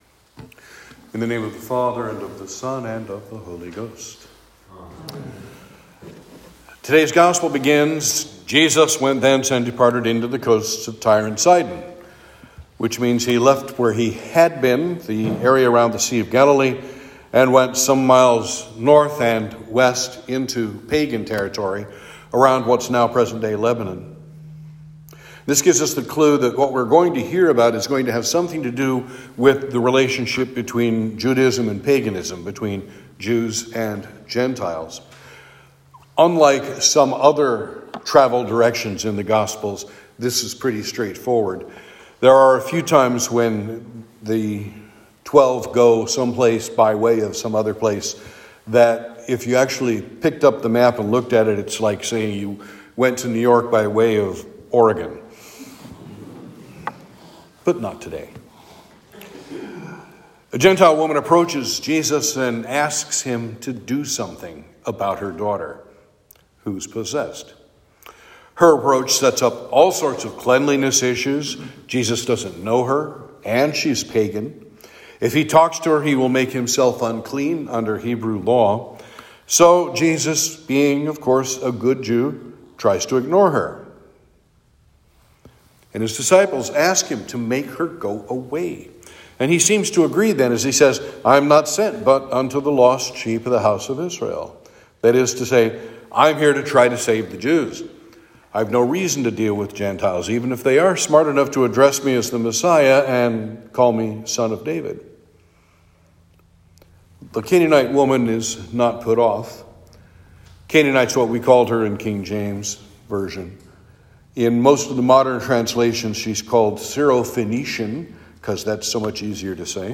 Saint George Sermons Sermon for Lent 2